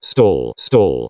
A320-family/Sounds/Cockpit/stall_voice.wav at 01bbc21269f07833d5abc2b05fd325385e36ea3d
stall_voice.wav